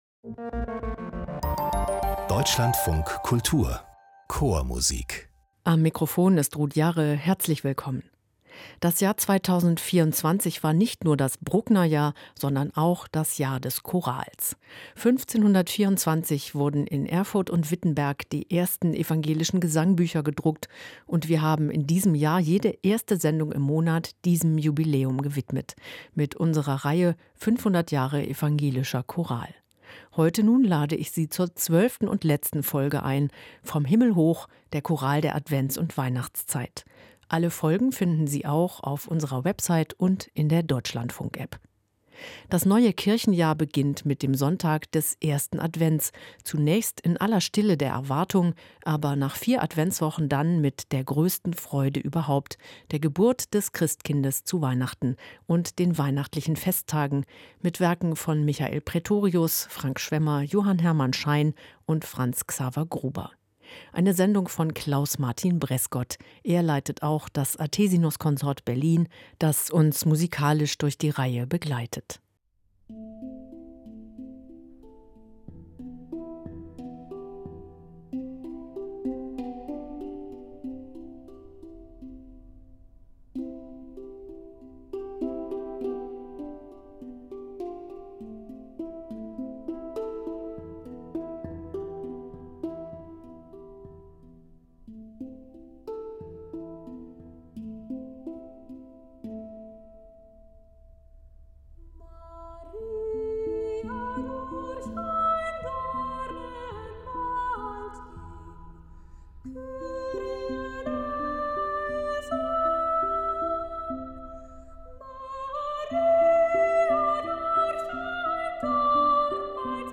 Zahlreiche Hörbeispiele, zumeist extra für diese Sendereihe entstanden, zeichnen ein facettenreiches Bild des protestantischen Liedguts und machen deutlich, warum es zum Stammrepertoire von Laien- und professionellen Chören gehört und bis heute gesungen wird.